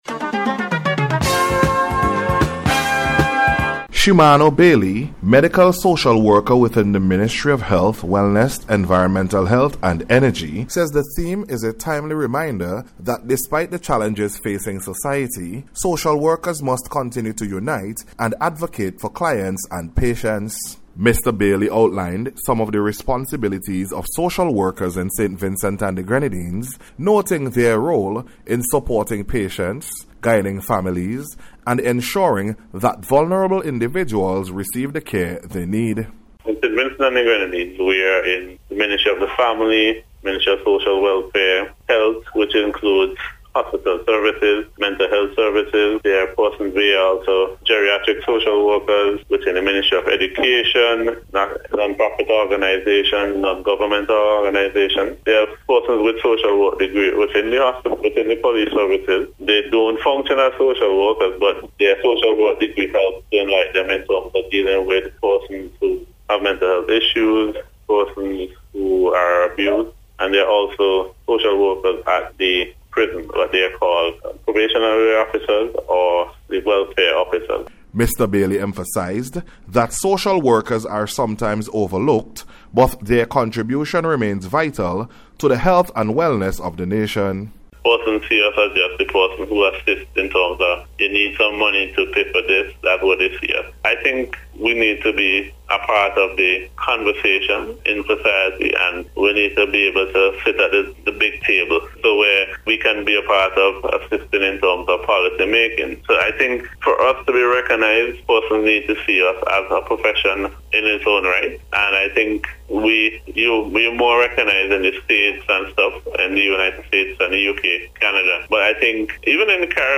SOCIAL-WORKERS-DAY-SPECIAL-REPORT.mp3